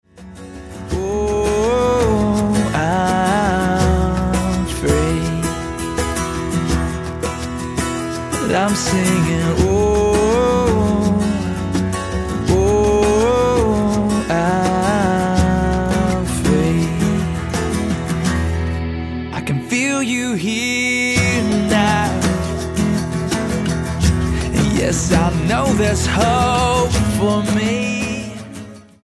Rock Album
Style: Pop